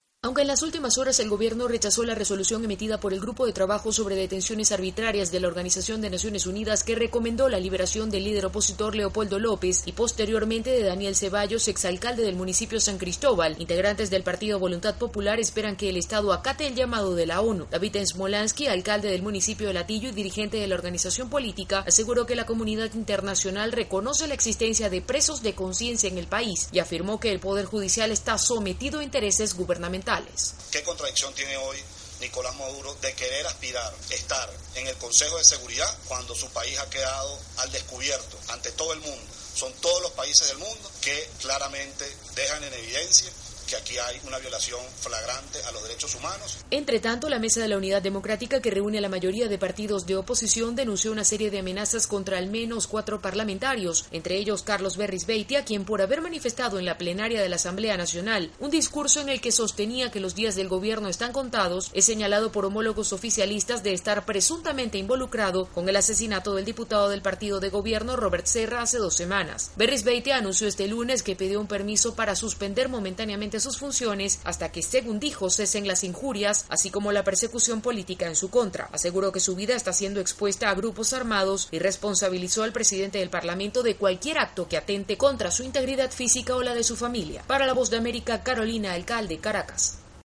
La oposición venezolana continua denunciando persecución política, una de las causas de la protesta callejera cuya convocatoria fue ratificada para el sábado próximo. Desde Caracas informa